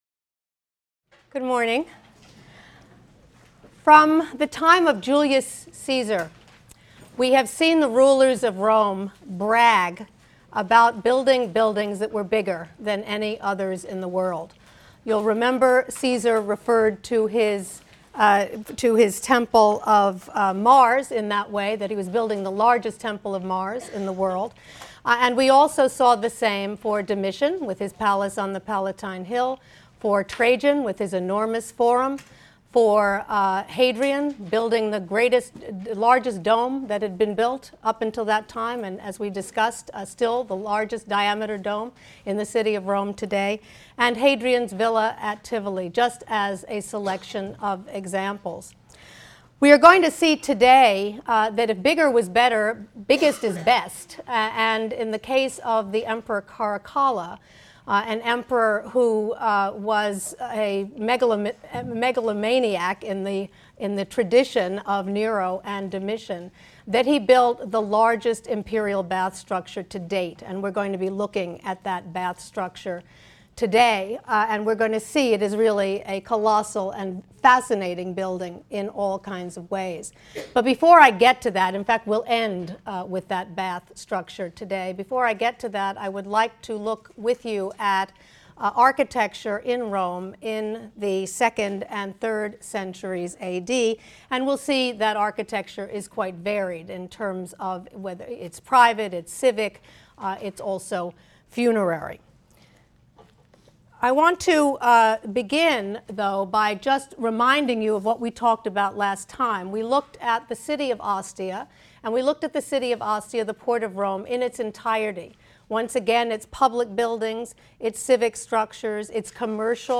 HSAR 252 - Lecture 17 - Bigger Is Better: The Baths of Caracalla and Other Second- and Third-Century Buildings in Rome | Open Yale Courses